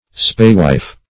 \Spae"wife`\